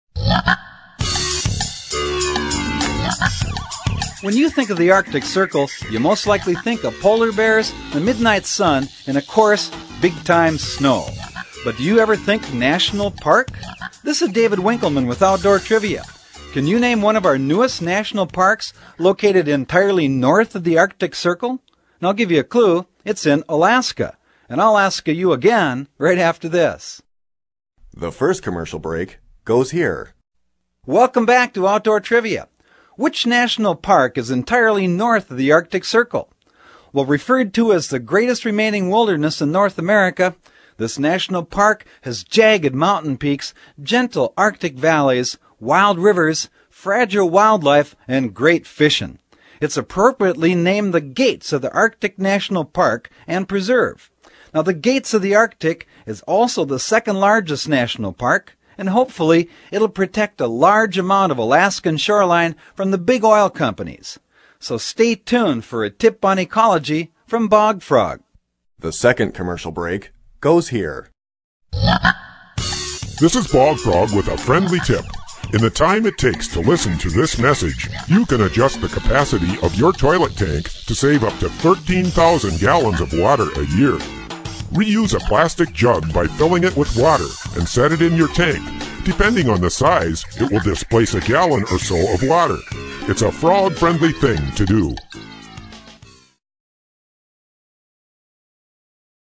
In fact, the question and answer trivia format of this program remains for many people, a most enjoyable, yet practical method of learning.
Bog Frog's voice is distinctive and memorable, while his messages remain positive and practical, giving consumers a meaningful symbol to remember.